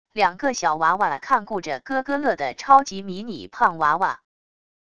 两个小娃娃看顾着咯咯乐的超级迷你胖娃娃wav音频